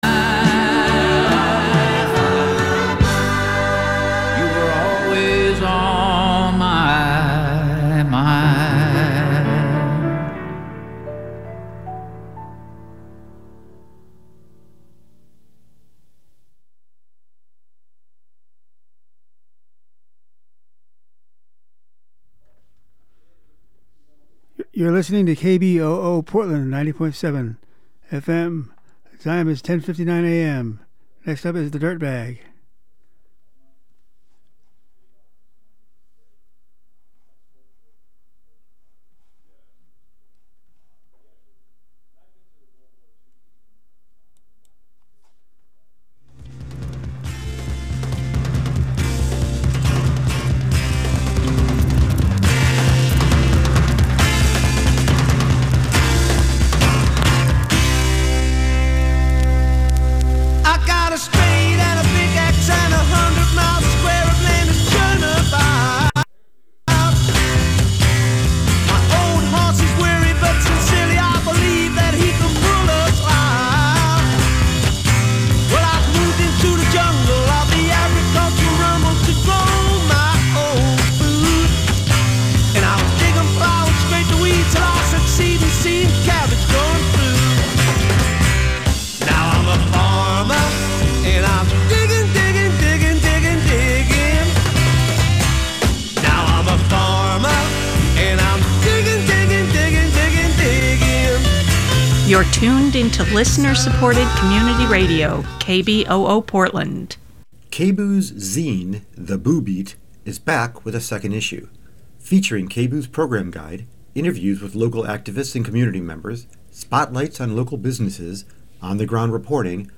As always, we'll have a new Plant of the Month (almonds ) and a new Garden Stumper--the first listener who calls in with the correct answer gets a free sharpening of a gardening hand tool from Cully Farm Store in Northeast Portland.